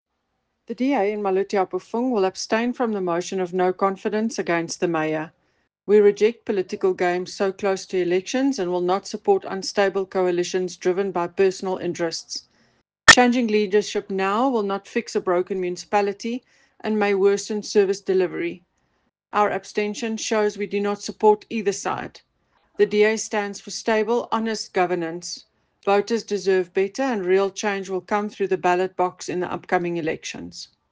Afrikaans soundbites by Cllr Eleanor Quinta and